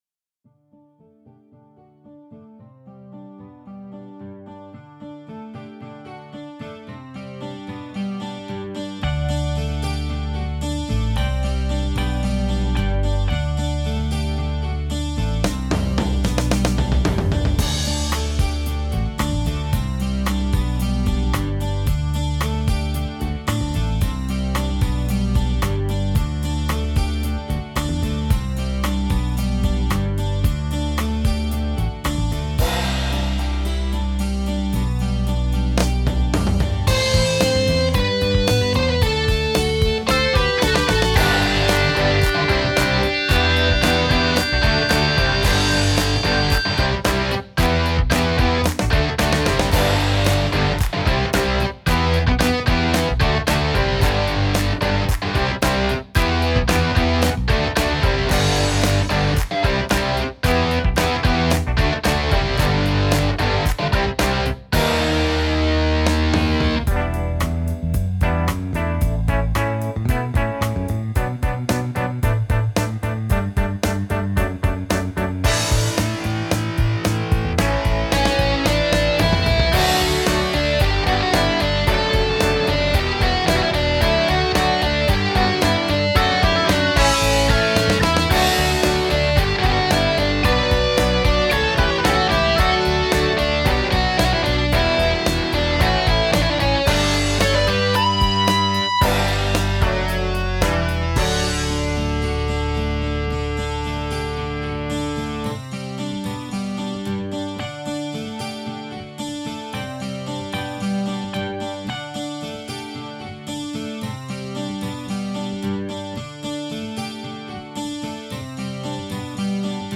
Filed under: Cover, MIDI Music, Remix